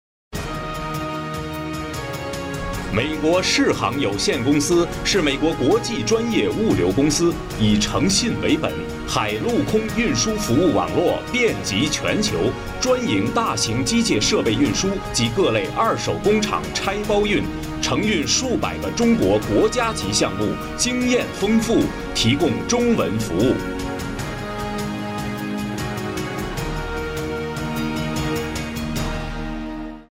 Best Voice in Chinese (Mandarin) Warm, Bright, Deep, Smooth and Professional.
Sprechprobe: eLearning (Muttersprache):